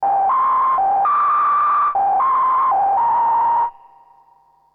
Как накрутить необычный лид?